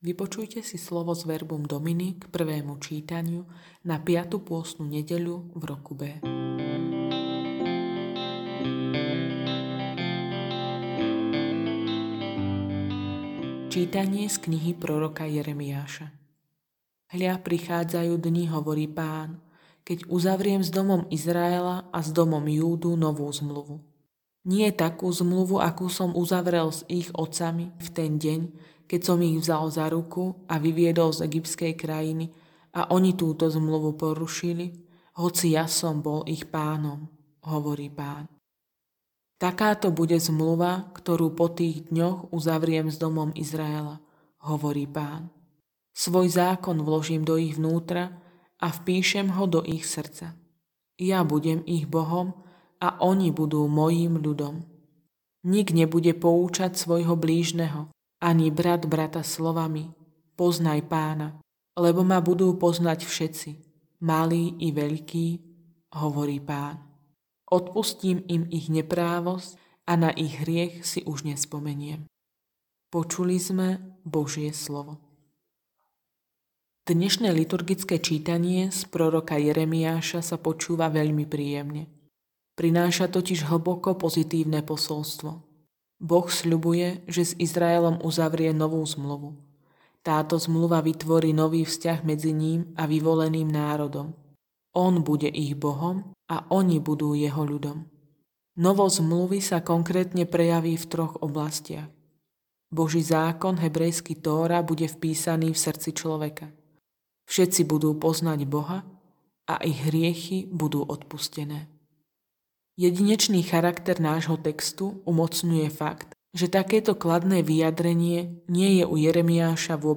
Audionahrávka zamyslenia…